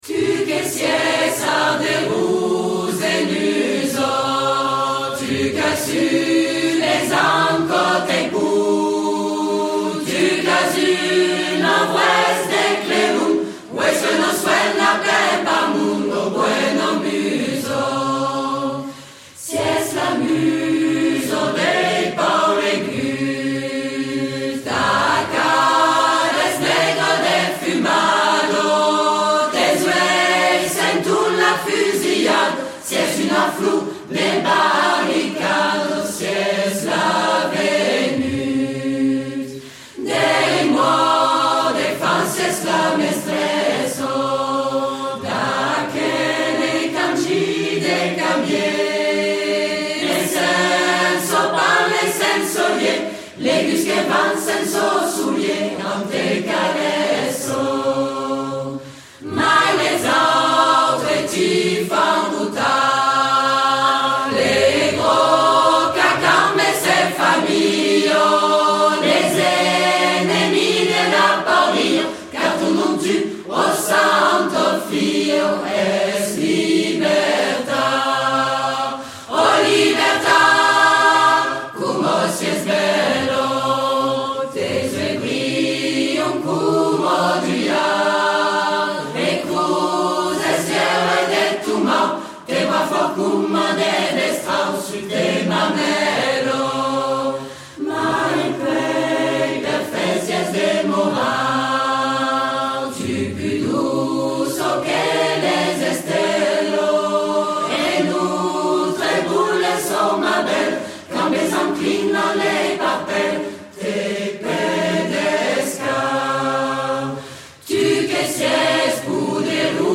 L’une des versions d’origine interprétée a cappella